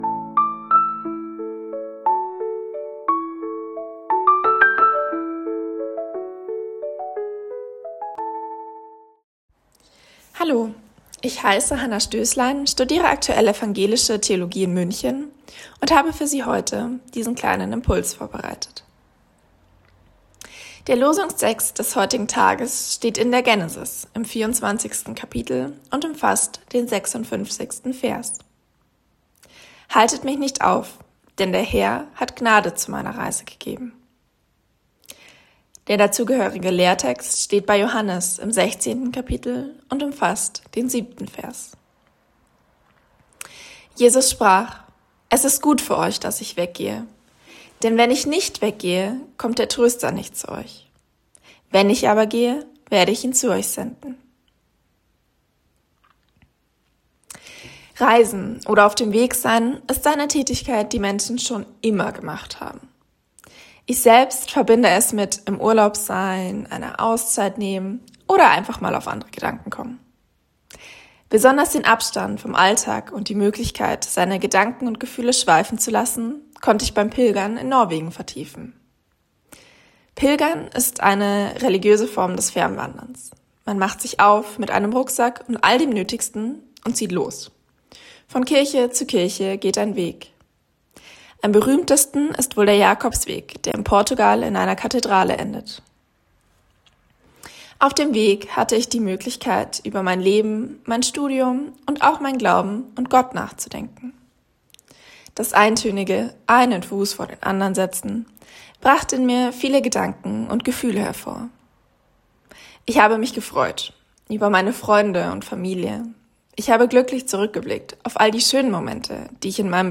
Losungsandacht für Montag, 20.04.2026